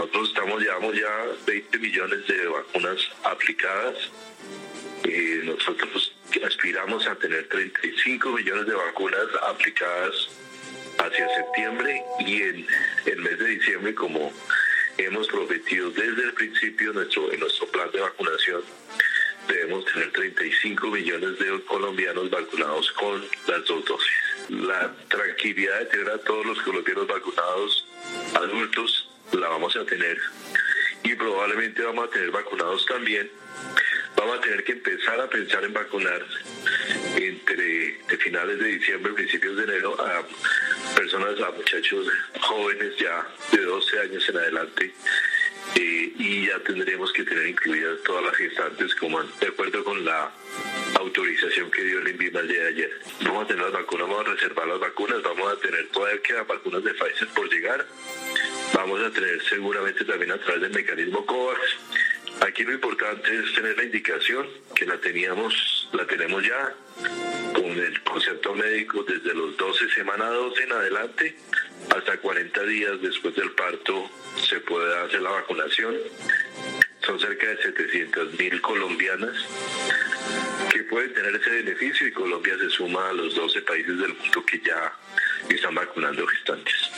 Audio del ministro de Salud y Protección Social, Fernando Ruiz Gómez.